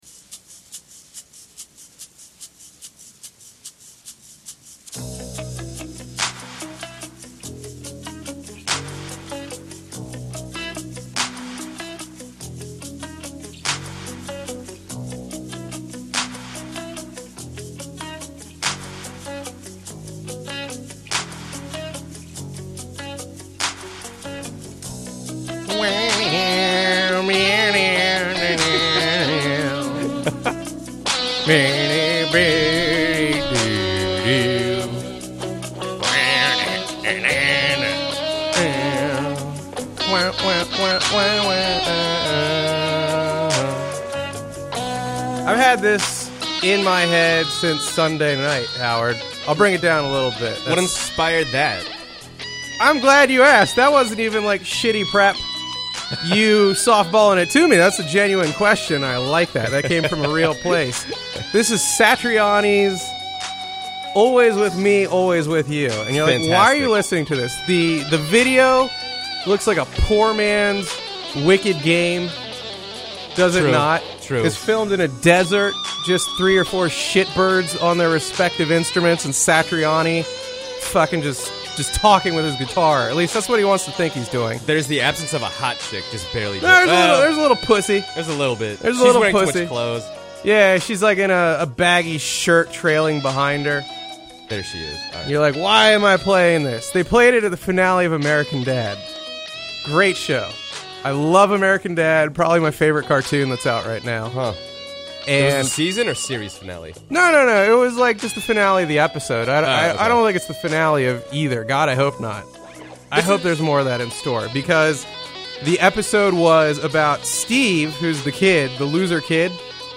That sounded really, really gay, so let me specify: That just means two guys shooting the s. No penetration.